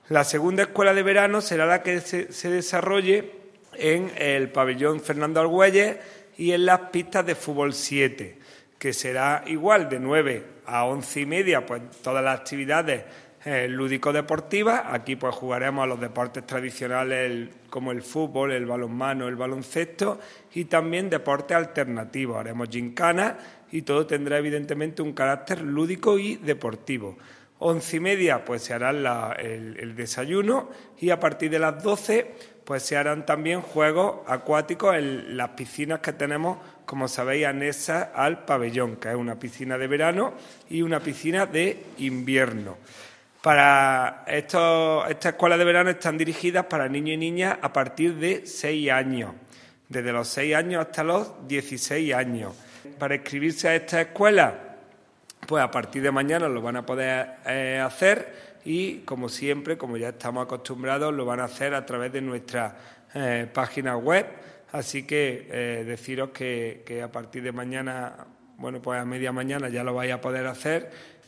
El teniente de alcalde delegado de Deportes, Juan Rosas, ha presentado hoy en rueda de prensa las Escuelas Deportivas de Verano 2022, iniciativa que promueve el Área de Deportes del Ayuntamiento de Antequera con el objetivo de facilitar la conciliación familiar y laboral durante el mes de julio, época ya de vacaciones en los colegios.
Cortes de voz